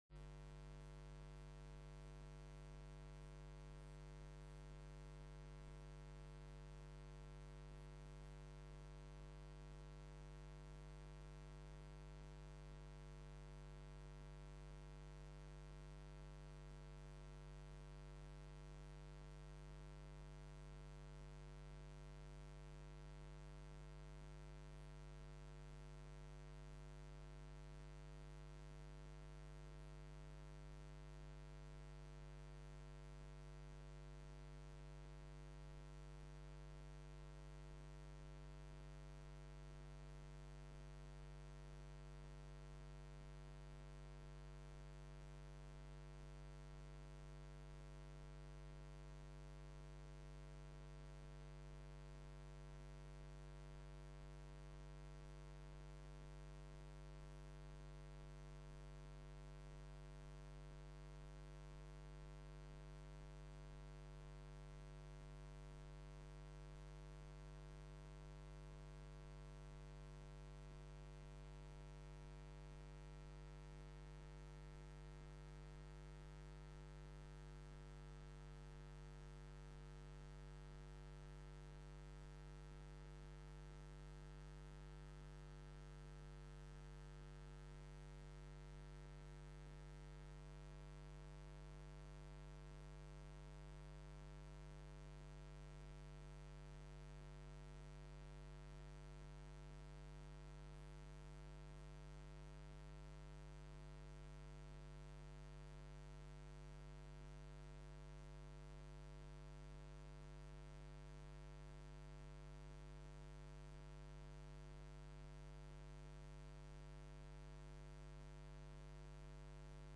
Gemeenteraad 10 september 2012 20:30:00, Gemeente Albrandswaard